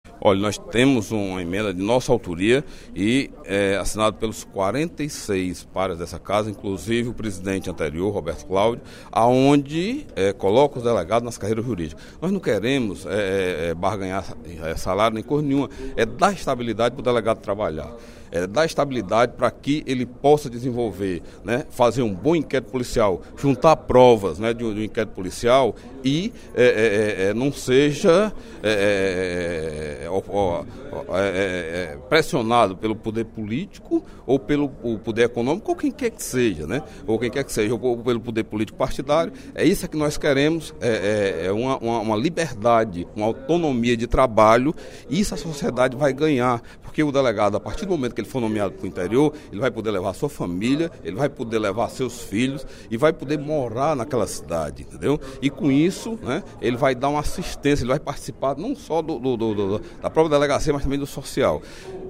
O deputado Delegado Cavalcante (PDT) informou, durante o primeiro expediente da sessão plenária desta terça-feira (03/12) da Assembleia Legislativa, que apresentou emenda aditiva à mensagem 7.353/12, que acompanha a emenda constitucional 1/12, do Poder Executivo, que regulamenta atividade dos delegados de Polícia Civil.